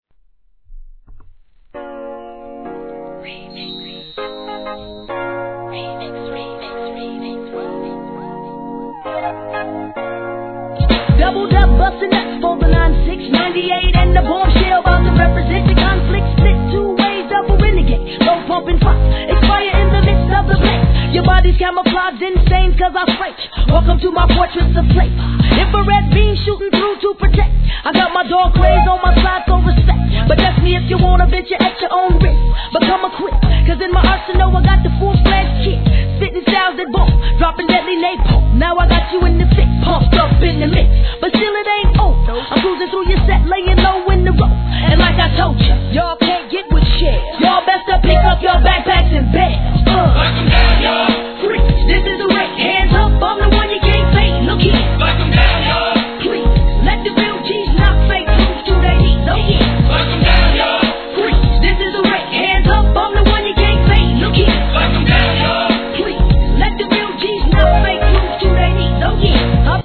G-RAP/WEST COAST/SOUTH
メロ〜なトラックにキャッチーなフックの